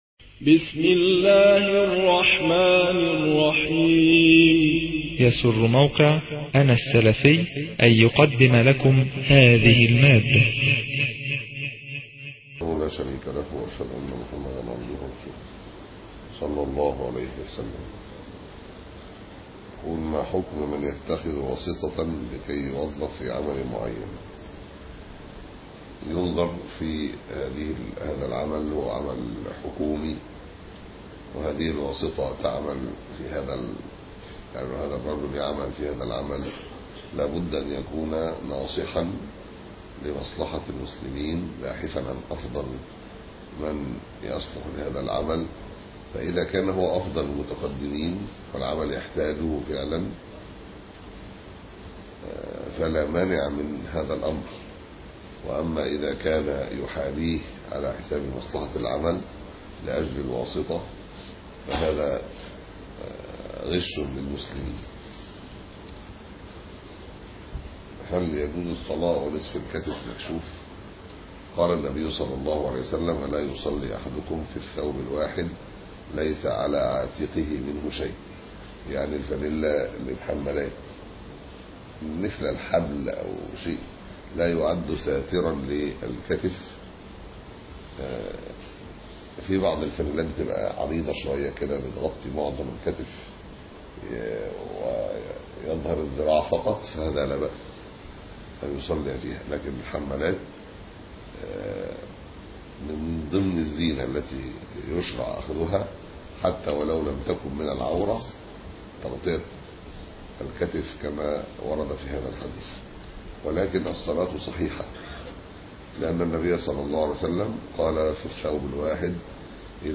006- الرد على الأسئلة والفتاوى (معتكف 1433) - الشيخ ياسر برهامي